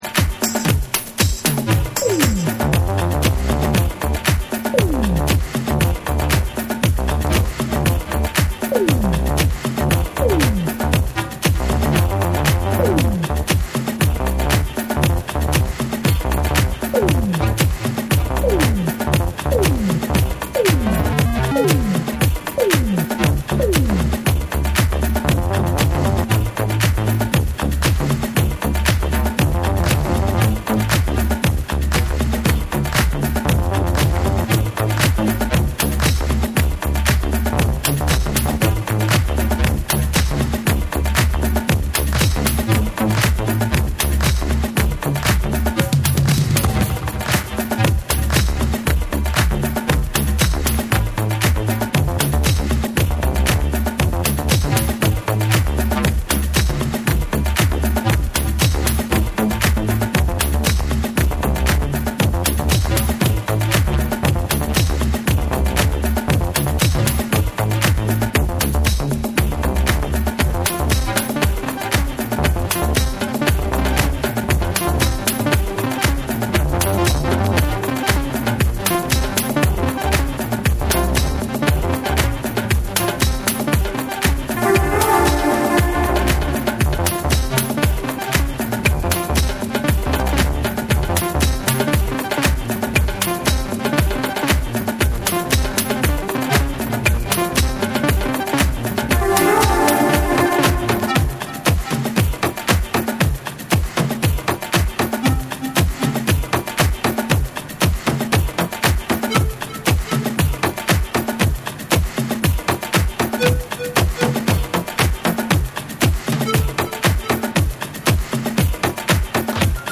重心低く叩き打つようなトライバル・トラックにトリップ感のあるフレーズが交わる1(sample 1)。
TECHNO & HOUSE / DISCO DUB